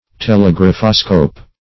Telegraphoscope \Tel`e*graph"o*scope\, n. [Gr. th^le far +